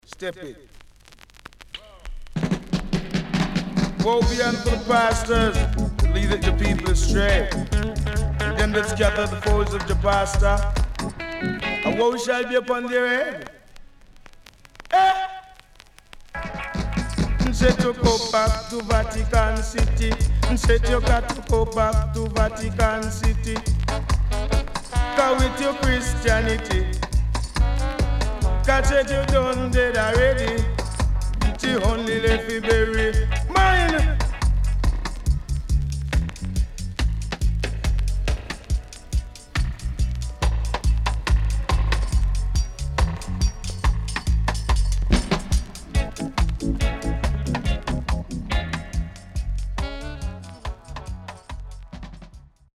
Killer Deejay & Dubwise
SIDE A:所々チリノイズがあり、少しプチノイズ入ります。